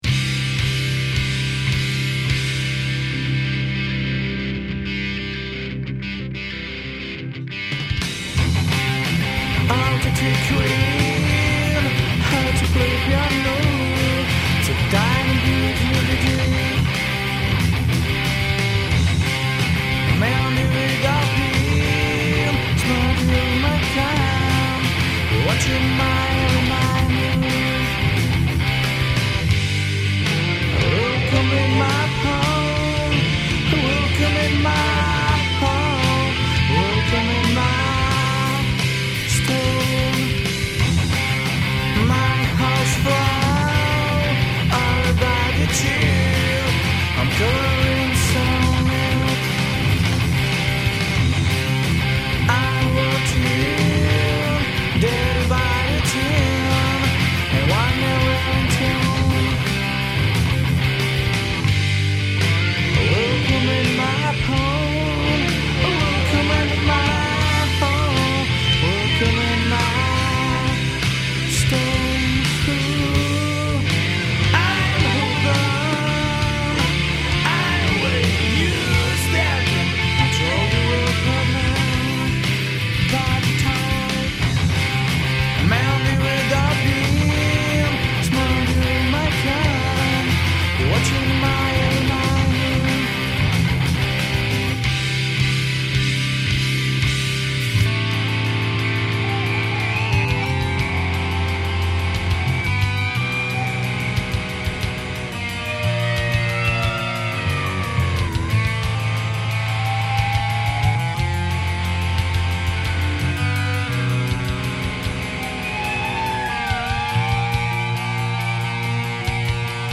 zpěv, kytara
bicí
basová kytara